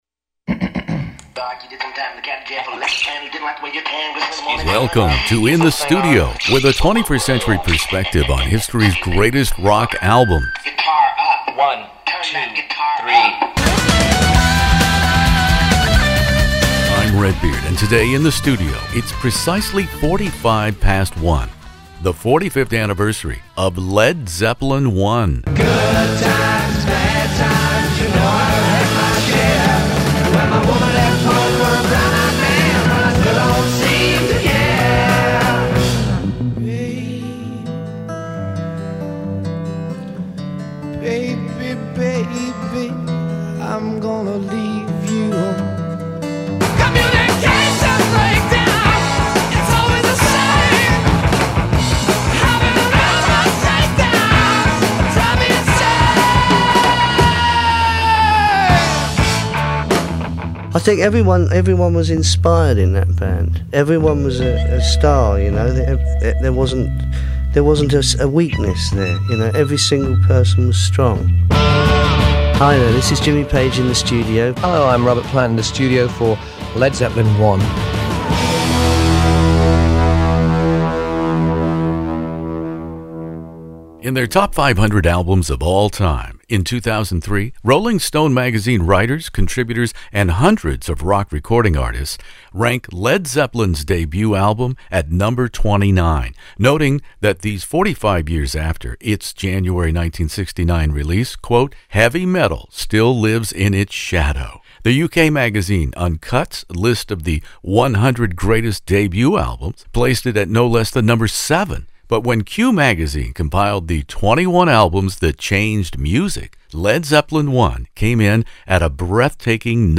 Page and Plant are my guests In the Studio for this classic rock intervie